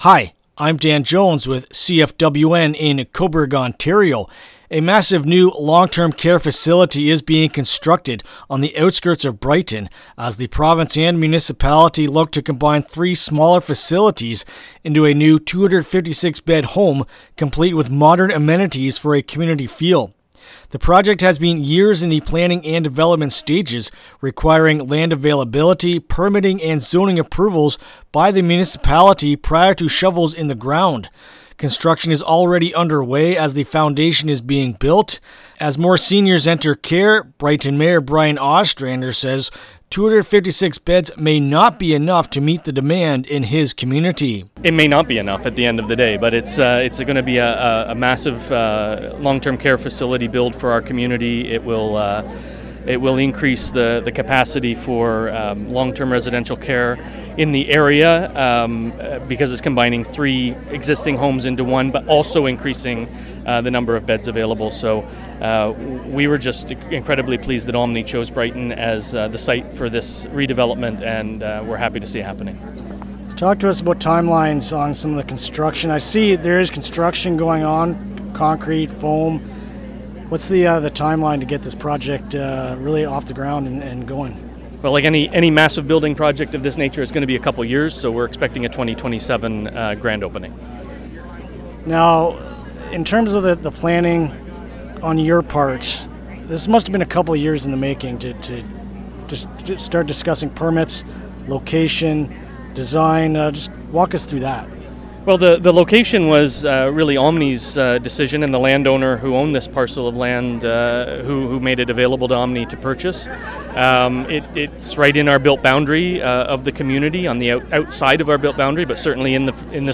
Mayor Brian Ostrander spoke to the significance of this project.
Brighton-Long-term-Care-Interview-LJI.mp3